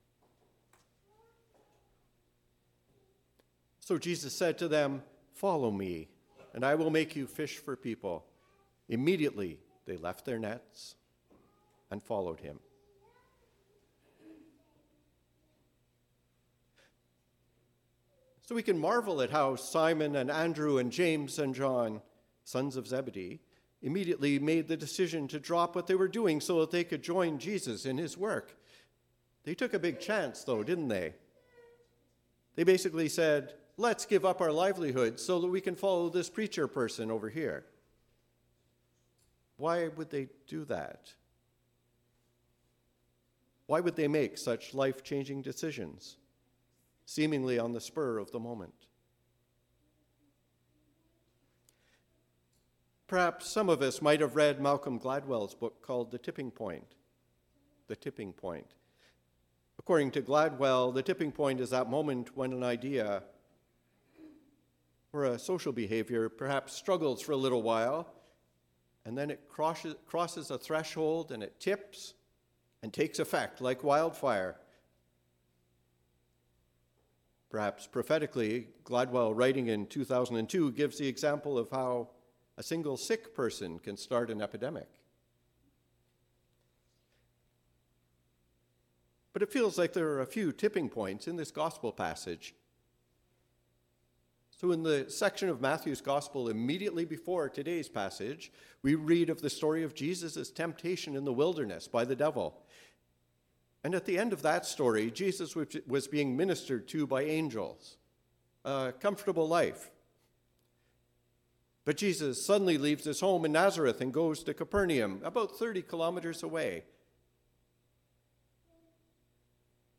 The Story of Jesus’ Call for Disciples: A Sermon on Matthew 4:12—23